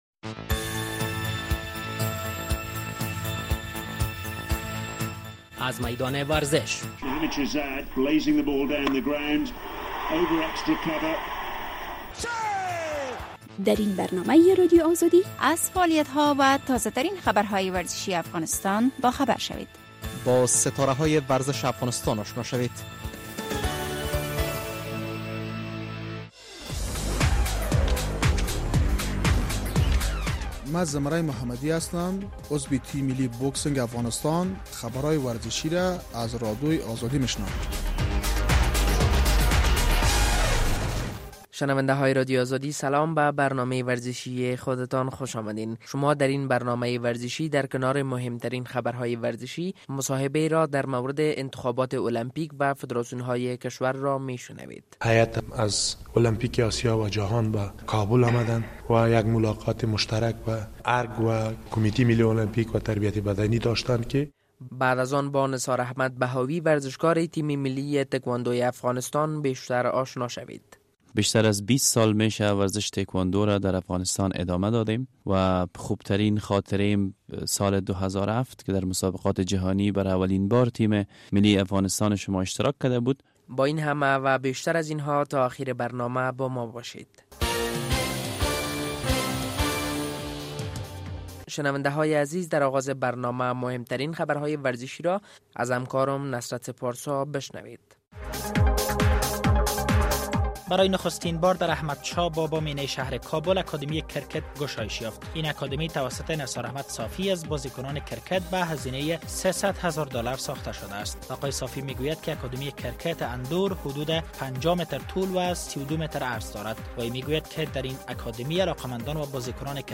در برنامه ورزشی این هفتۀ رادیو آزادی در کنار مهمترین خبرهای ورزشی مصاحبه را در مورد آغاز آماده‌گی‌ها برای برگزاری ...